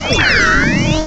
cry_not_rotom.aif